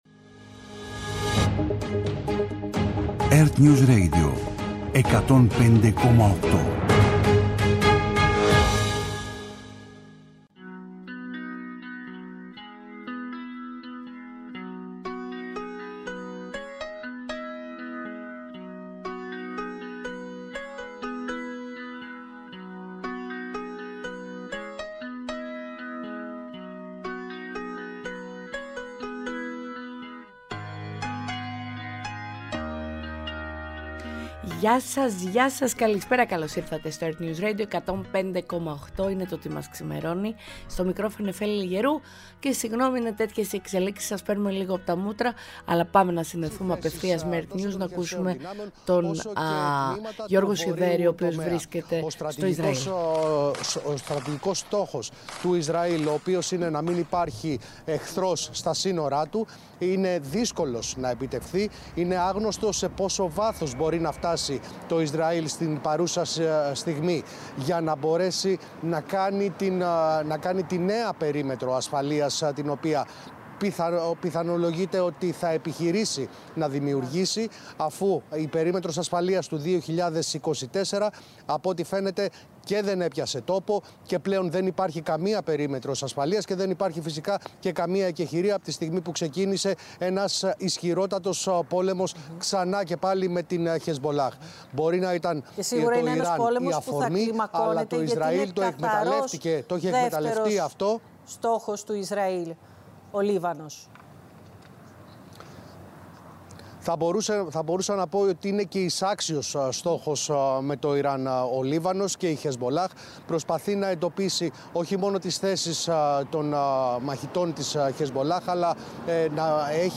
Απόψε συνδέσεις με ERTNEWS και τους Ανταποκριτές στα κρίσιμα σημεία της ανάφλεξης στη Μέση Ανατολή.